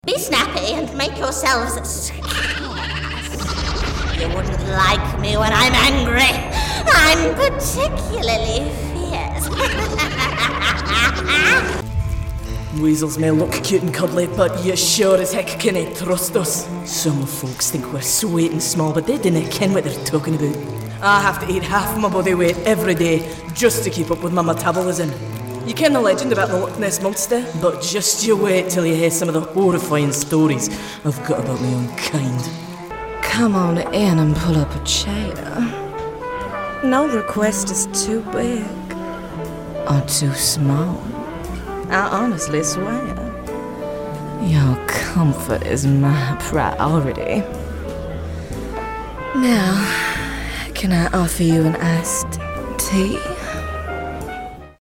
Her sound is very easy on the Ear. She also has an ability to cover a very wide age range and creates fantastically full and rich characters completely different from one to the other perfect for Gaming and Animation.